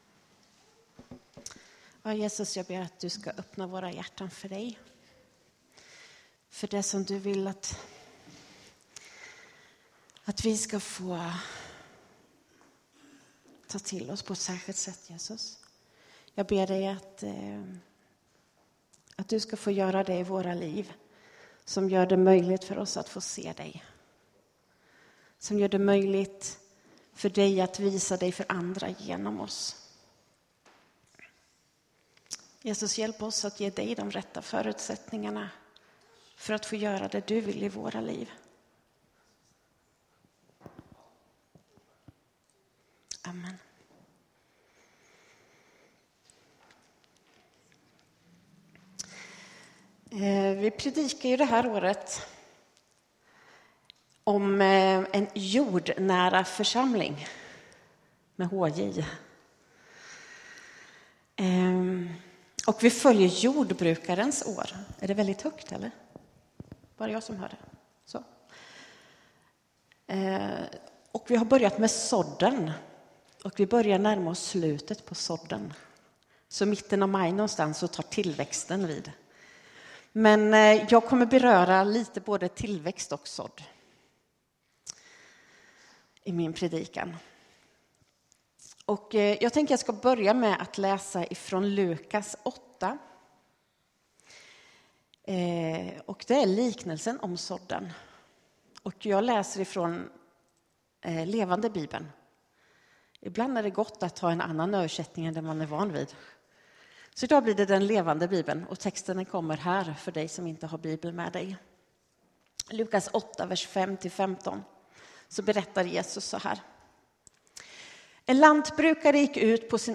PREDIKNINGAR